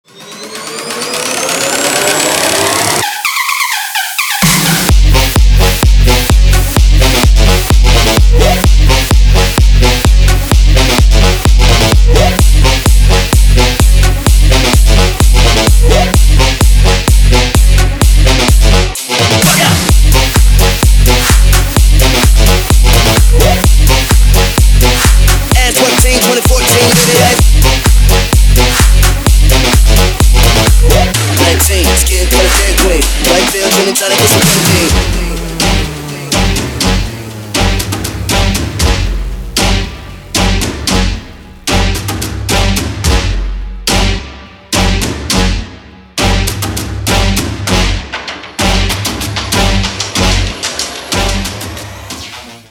• Качество: 320, Stereo
house